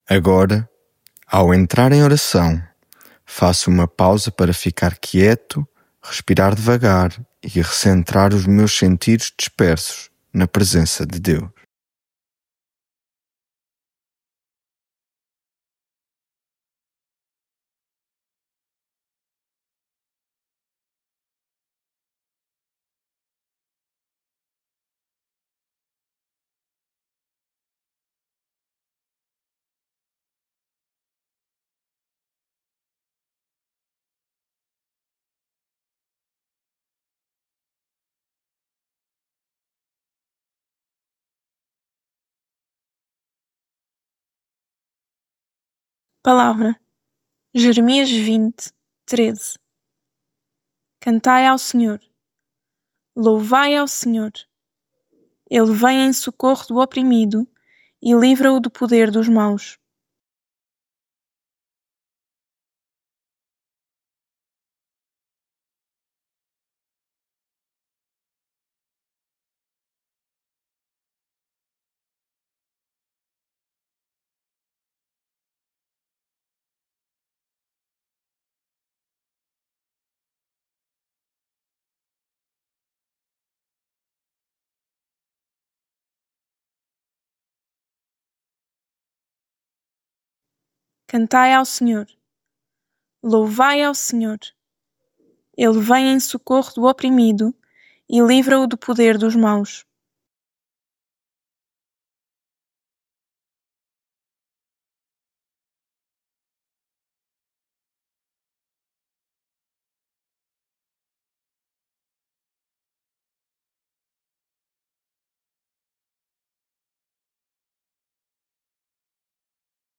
Devocional
lectio divina